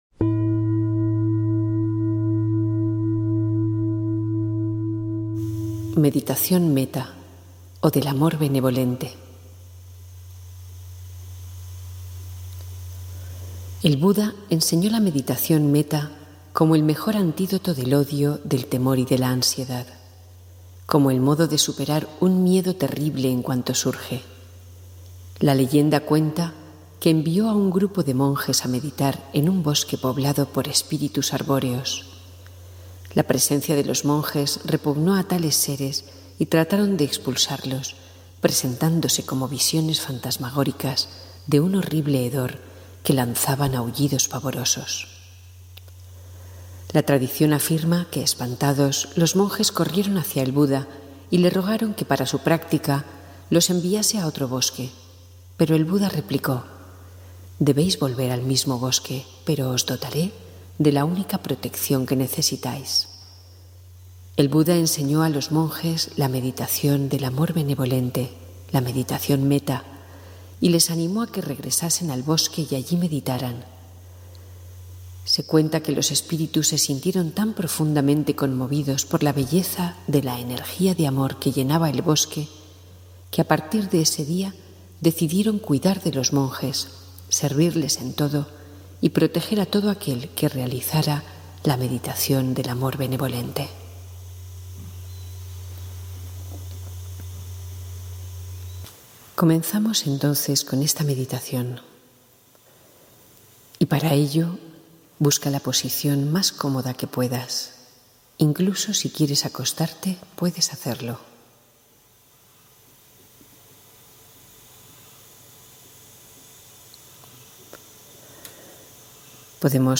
Meditación guiada para el amor benevolente y la paz interior